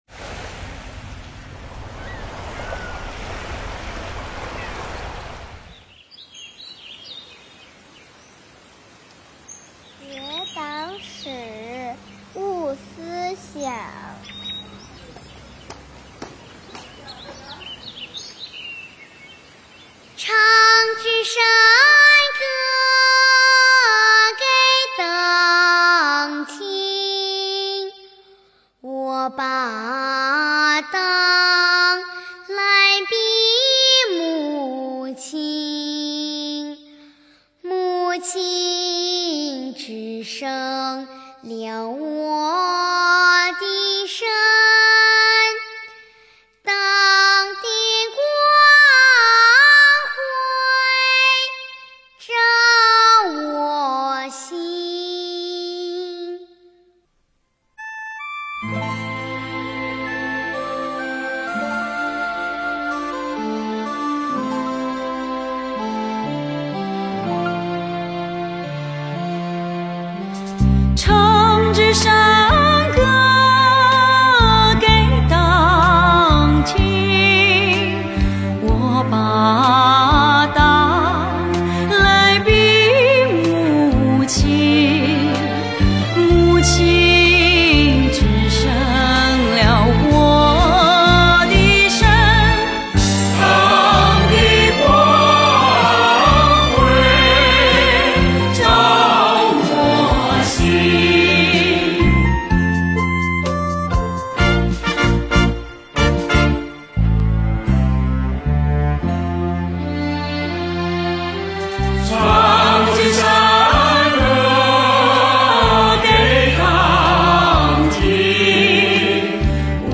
防城港市税务局举办2021年首期“港城税务大课堂（榜样讲堂），通过“红色歌曲大家唱、走进榜样的世界、谈感想感悟”三个环节，为大家带来一场榜样精神洗礼，品悟前行力量的精神盛宴。